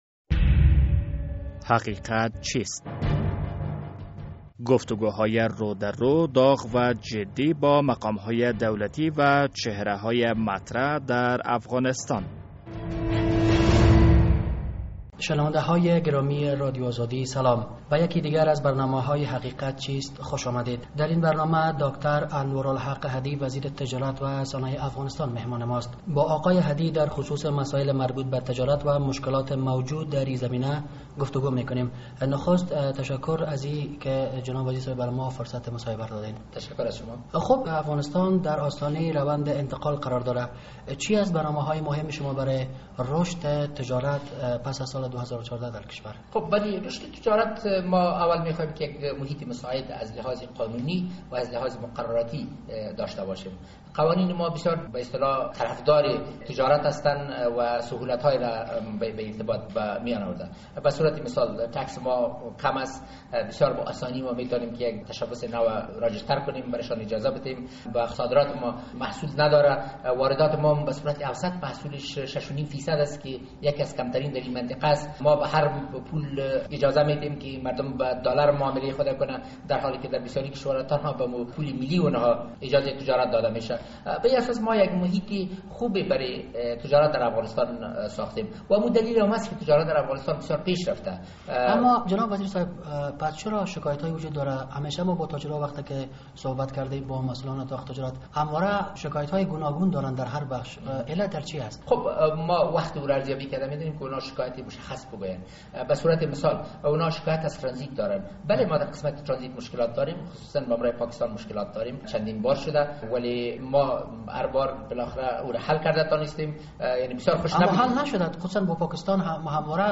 مهمان ما در این هفته داکتر انوارالحق احدی وزیر تجارت و صنایع افغانستان است.